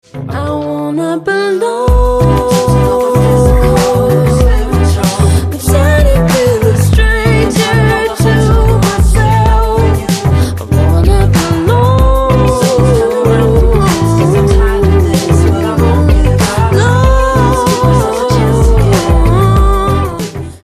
• Качество: 128, Stereo
поп
женский вокал
приятный голос